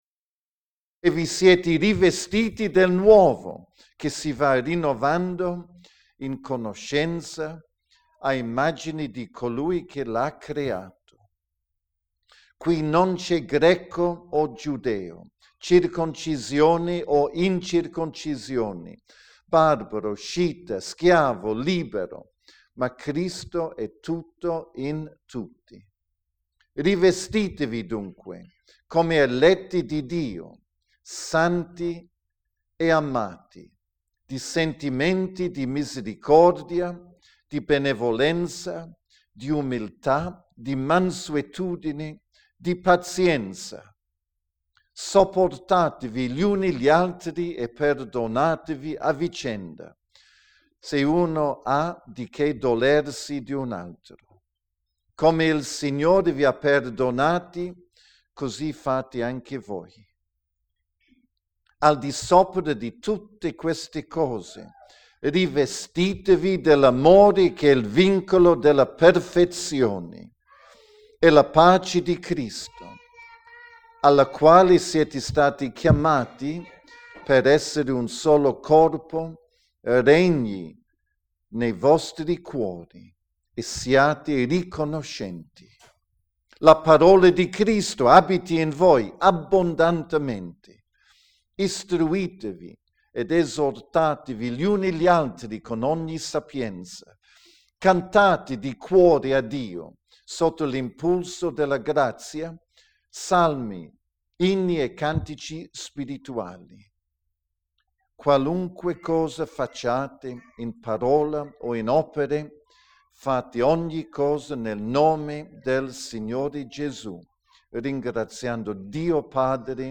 Predicazione 05 giugno 2016 - Il potere della Parola